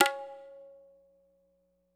Bongo [ La Flame ](1).wav